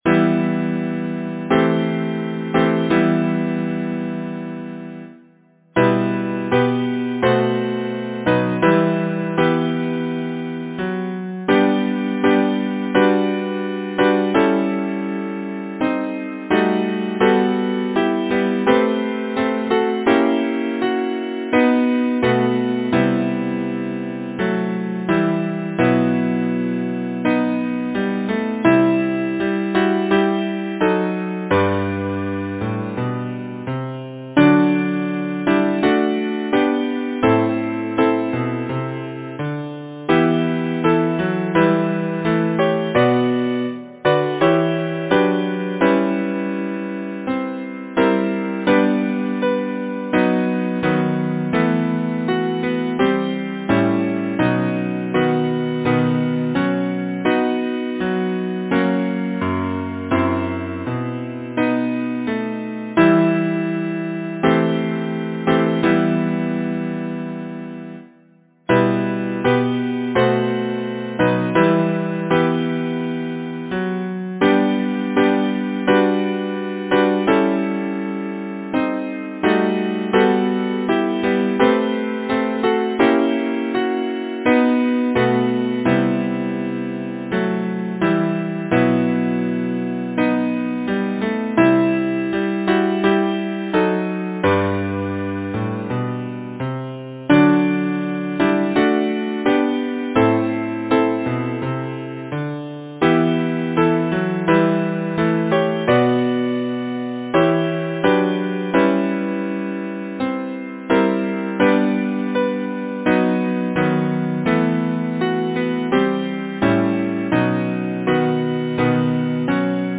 Title: Long, long the Night Composer: Daniel Gregory Mason Lyricist: Robert Burns Number of voices: 4vv Voicing: SATB Genre: Secular, Partsong
Language: English Instruments: A cappella